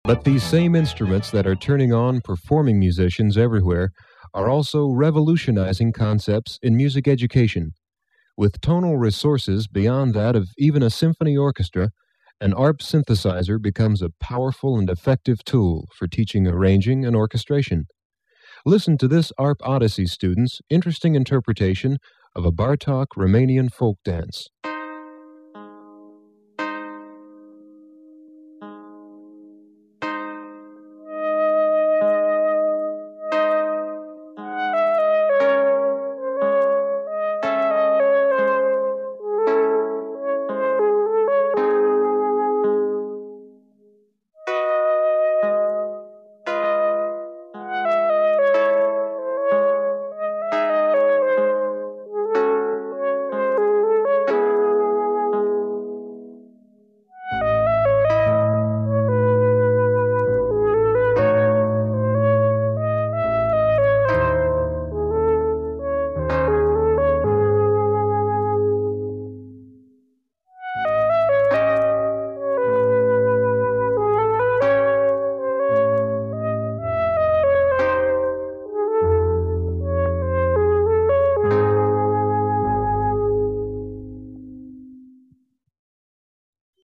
ARP 2600 Demo Tape - Education